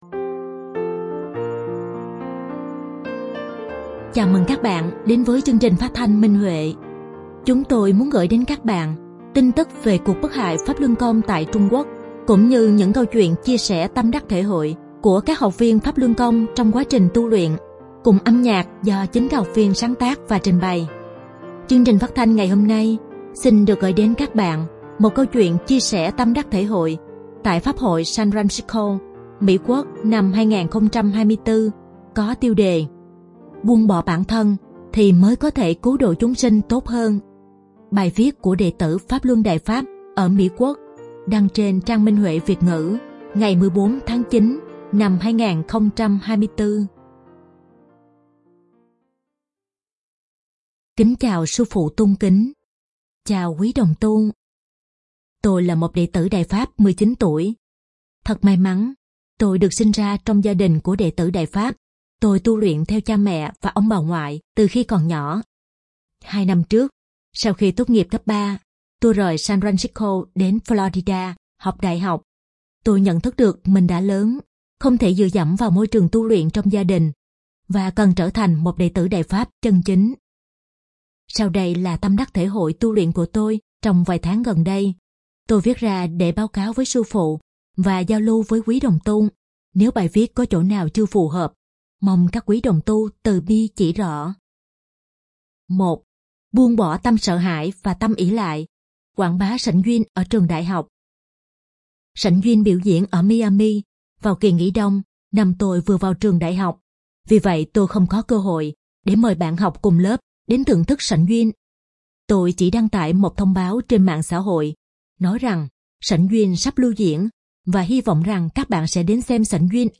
Chương trình phát thanh số 1314: Bài viết chia sẻ tâm đắc thể hội trên Minh Huệ Net có tiêu đề Buông bỏ bản thân thì mới có thể cứu độ chúng sinh tốt hơn, bài viết của đệ tử Đại Pháp tại Đại Lục.